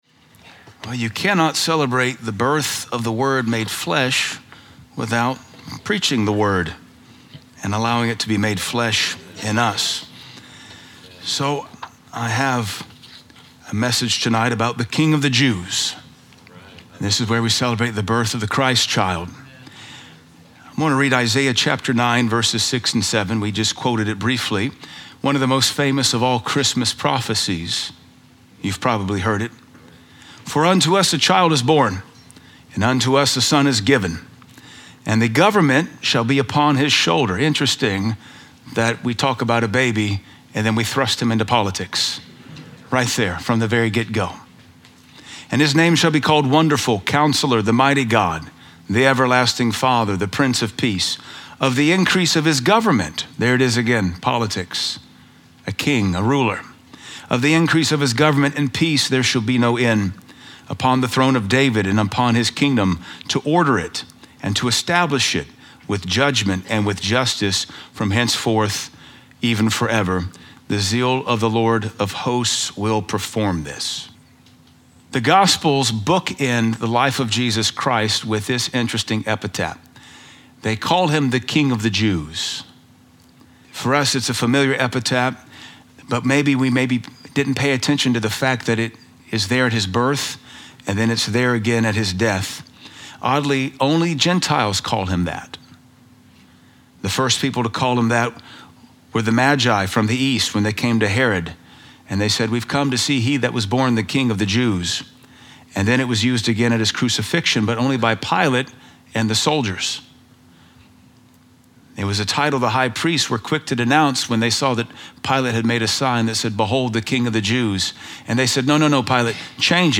14 Very Unorthodox Christmas Sermons - PodSchool